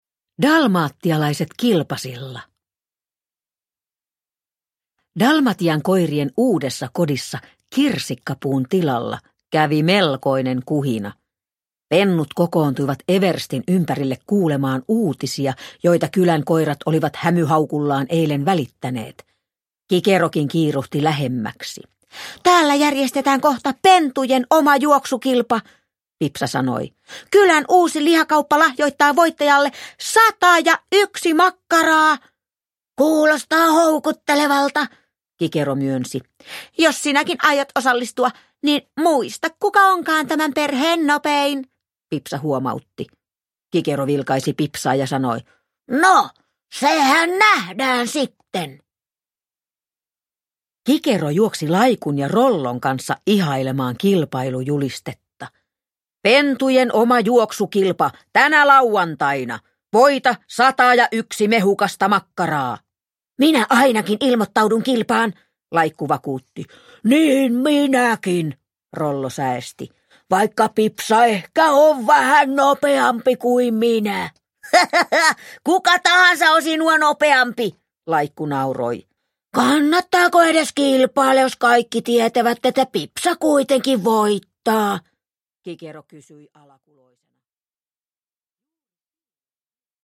Dalmatialaiset kilpasilla – Ljudbok – Laddas ner